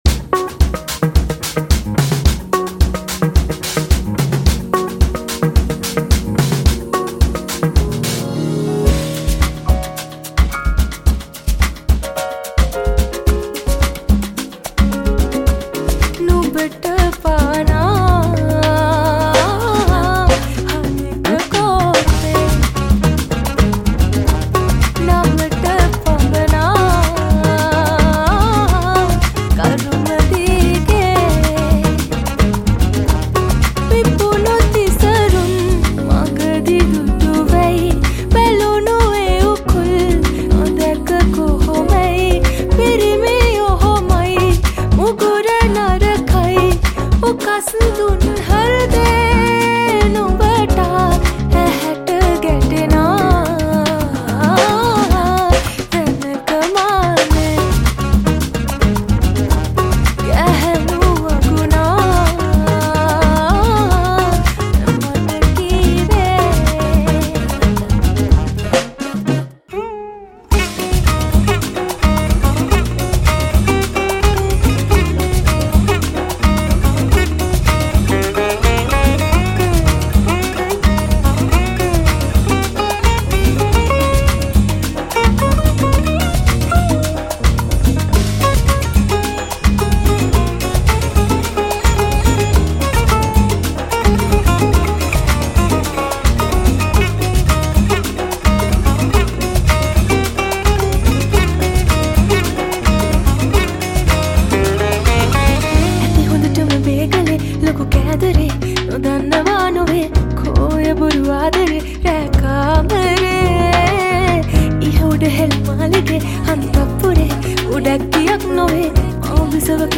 High quality Sri Lankan remix MP3 (5.7).
remix